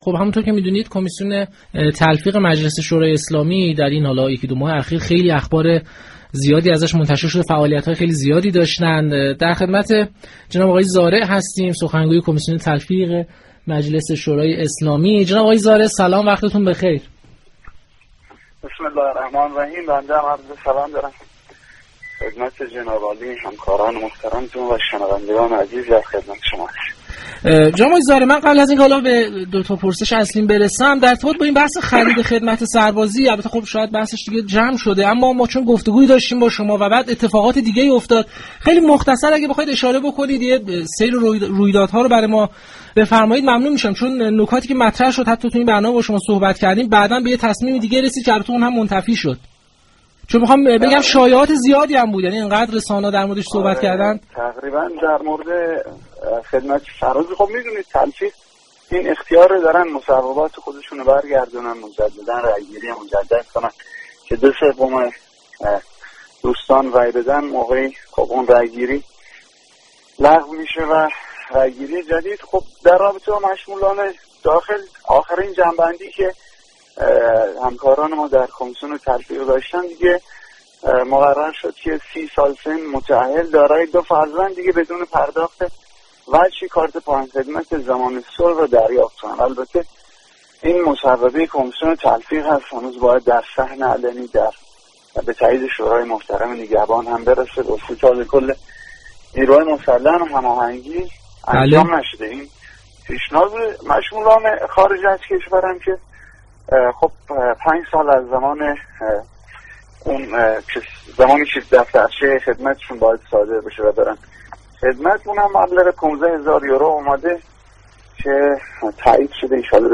به گزارش پایگاه اطلاع رسانی رادیو تهران، رحیم زارع سخنگوی كمیسیون تلفیق مجلس شورای اسلامی در گفتگو با پارك شهر رادیو تهران درباره آخرین وضعیت قانون خرید خدمت سربازی گفت: كمیسیون تلفیق این اختیارات را دارد كه مصوبات خود را بازگردانده و دوباره بررسی كند.